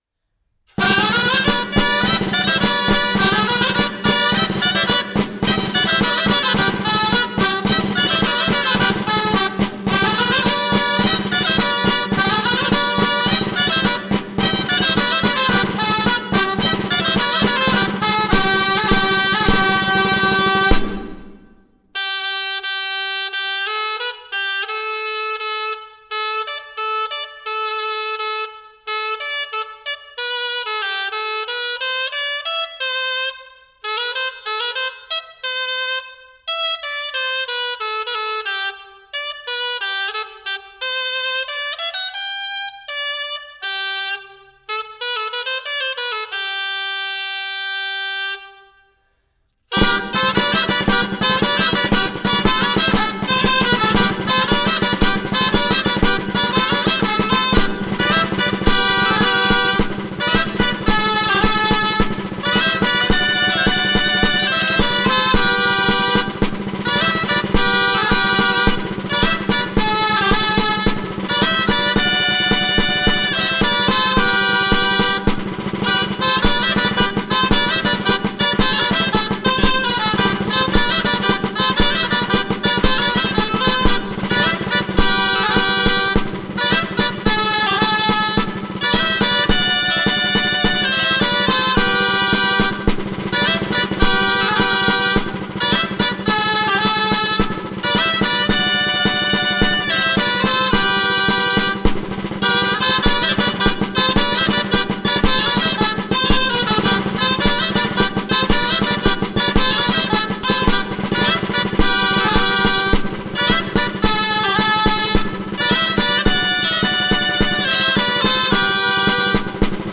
18. BALL DE CERCOLETS DE TARRAGONA Grallers Els Quatre Garrofers